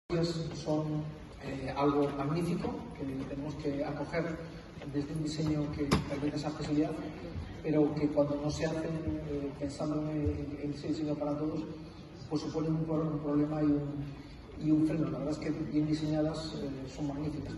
En su intervención en el acto